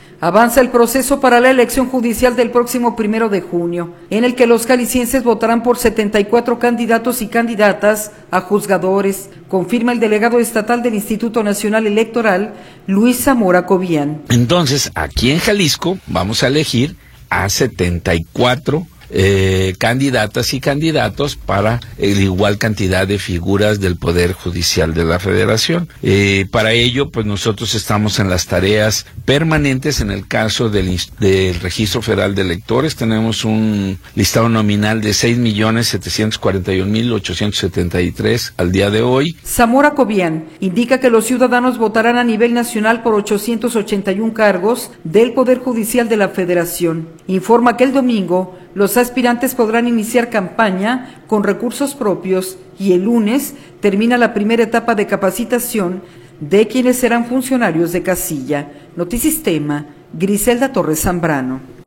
Avanza el proceso para la elección judicial del próximo primero de junio, en el que los jaliscienses votarán por 74 candidatos y candidatas a juzgadores, confirma el delegado estatal del Instituto Nacional Electoral, Luis Zamora Cobián.